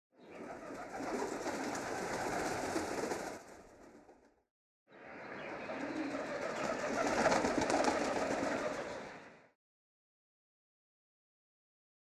Für den Übergang habe ich ein Taubenflug-Sample verwendet, dass ich schon seit Jahren im Hinterkopf habe und nun endlich einsetzen will.
Die Tauben hören sich alleine so an: